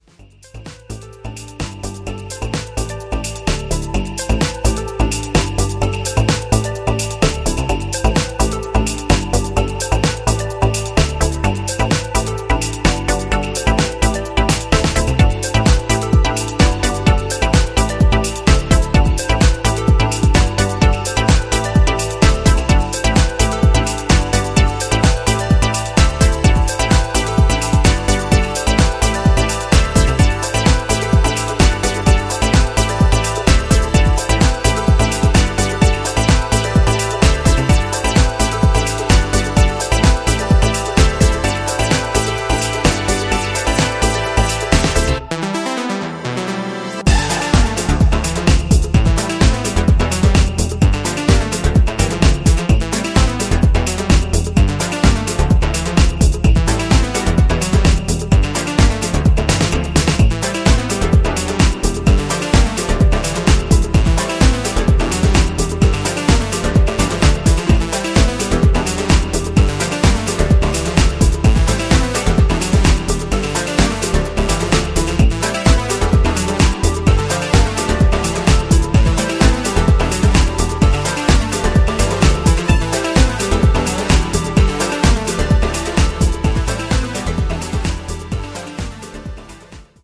ここでは、豊潤なシンセレイヤーが躍動するメロディックかつエネルギッシュなハウス・トラックスを展開。